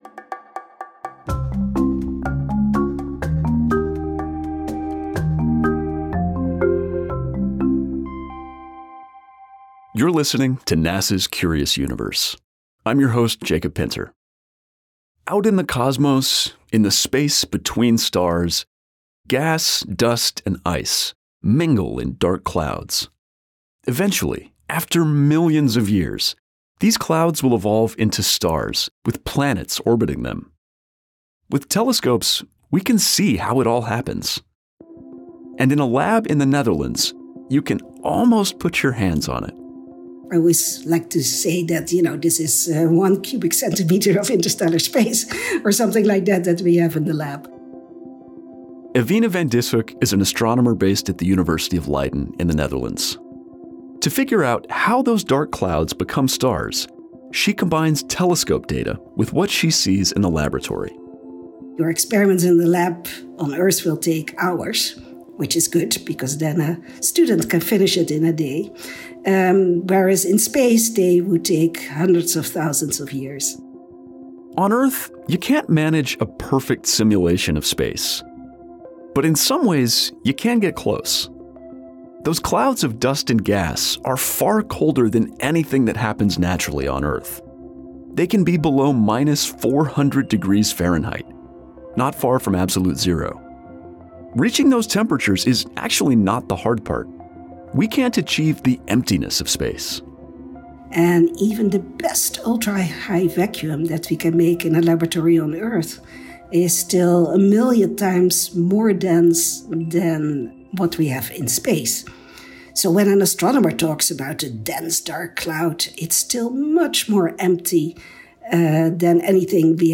Ewine van Dishoeck, an astronomer who studies molecules in space and who helped develop an instrument aboard NASA’s James Webb Space Telescope, explains how Webb is revealing new details about the formation of stars and planets. This research could help unlock a key question about Earth: how did our planet end up with water and the ingredients for life?